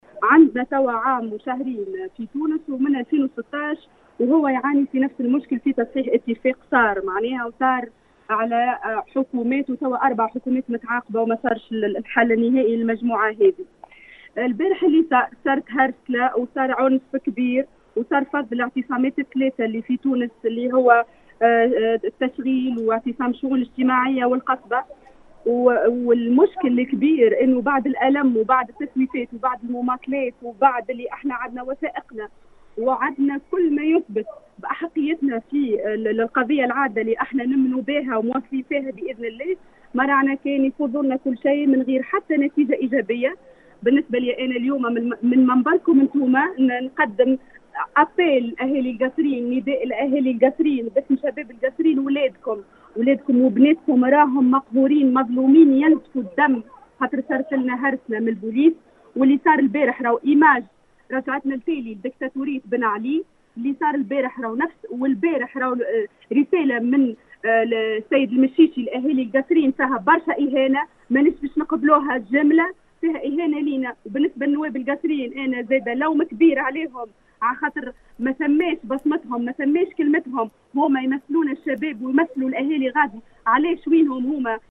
اكدت مساء اليوم  15 اكتوبر 2020 احدى حاملي الشهائد العليا ابنلء القصرين المعتصمين امام وزارة التشغيل في اتصال براديو السيليوم اف ام